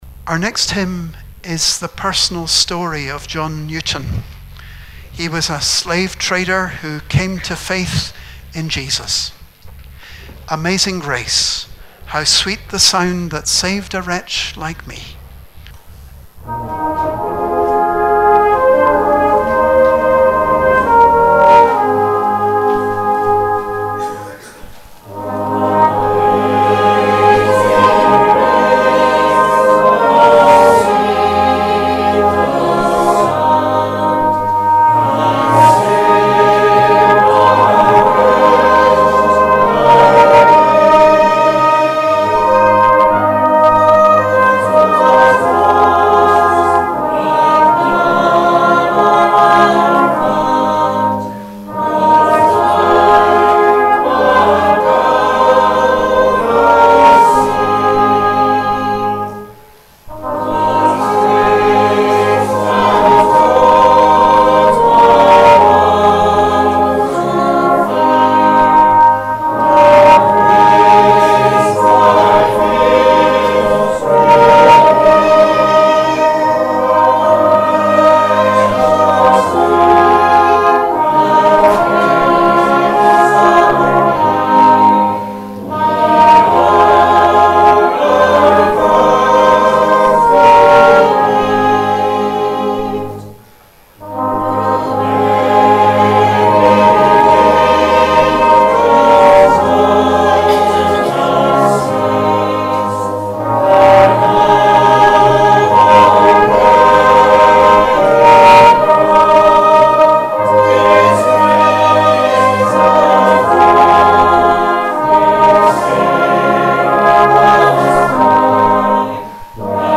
Remembrance Sunday - 10 November 2019
Amazing Grace' hymn 555 continued our praise, followed by the offering, and '